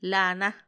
alha[allá]